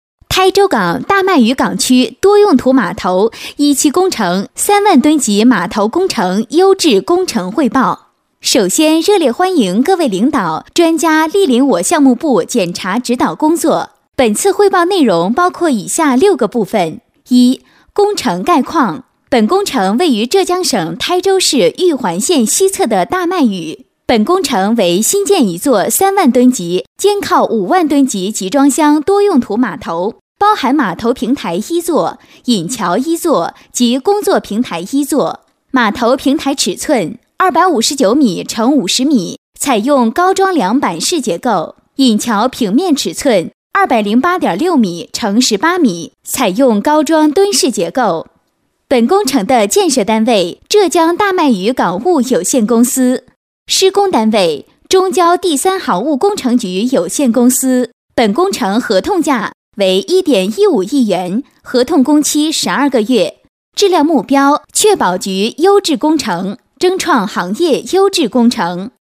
女国语132
【专题】台州港大麦屿港区